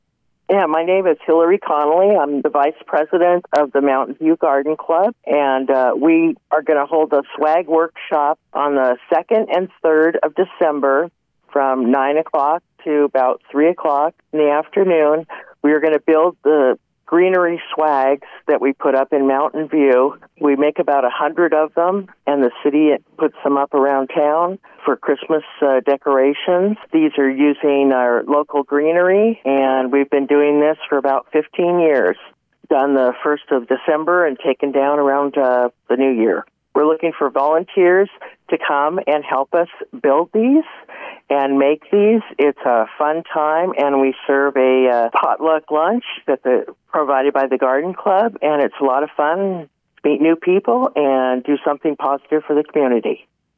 We spoke to a member of the Mountain View Garden Club for details on this event.